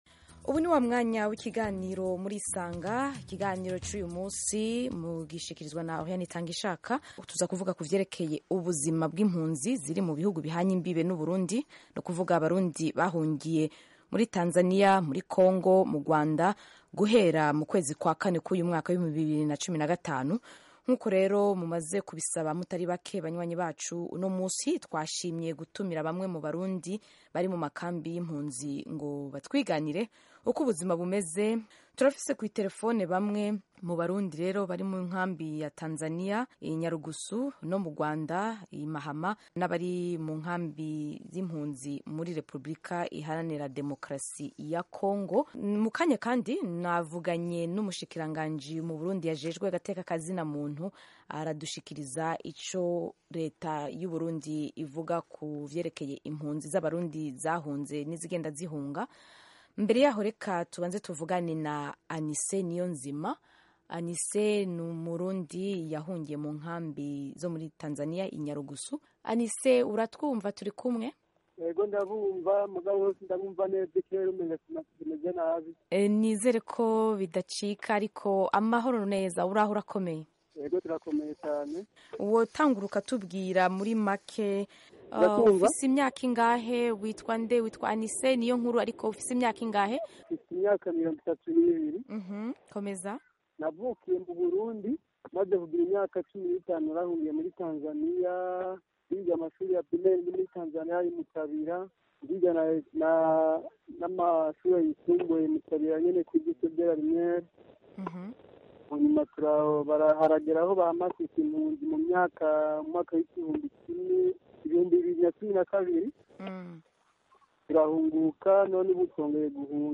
Refugee Call-In Show 50'37"